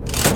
lever5.ogg